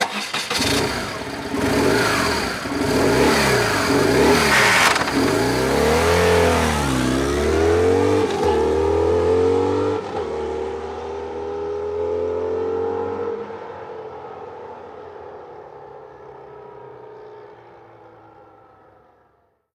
Moto marca BMW arrancando y saliendo
motocicleta
Sonidos: Transportes